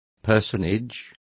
Complete with pronunciation of the translation of personage.